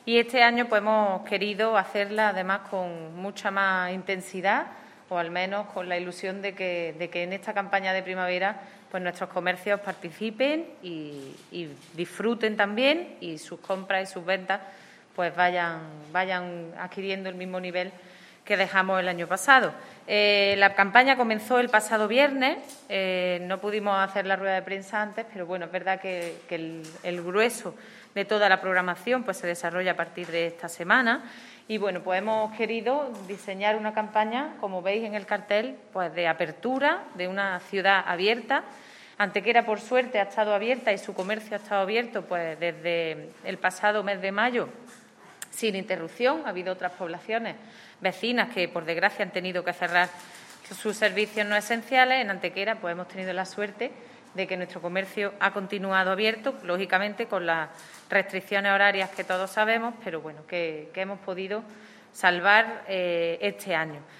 La teniente de alcalde delegada de Turismo, Patrimonio Histórico, Políticas de Empleo y Comercio, Ana Cebrián, ha presentado hoy en rueda de prensa la Campaña de Primavera con la que se trata de impulsar, desde el Ayuntamiento, el comercio de nuestra ciudad durante las próximas semanas coincidiendo con el inicio y transcurso de la nueva estación.
Cortes de voz